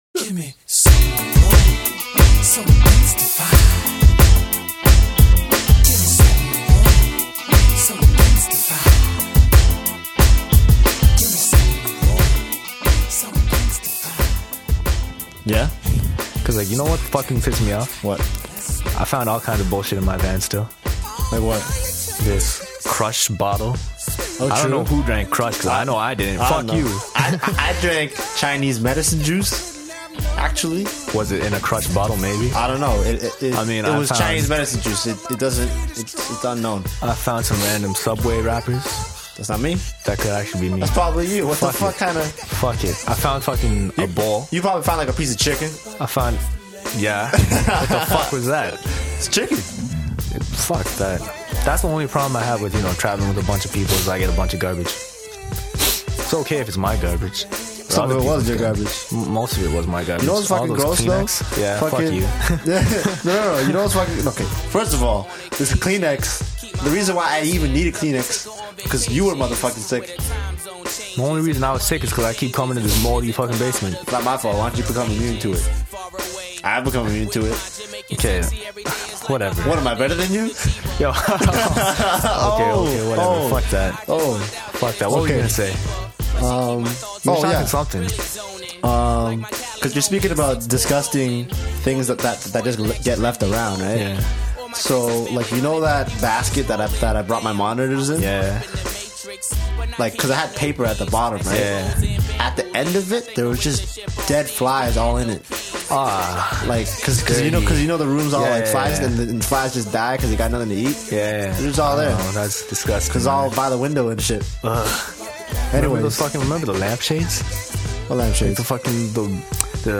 I started recording podcast back in 2008 with my production partner to help promote our work. It used to be very focused on hip hop and production, but it’s turned into more of just random conversation.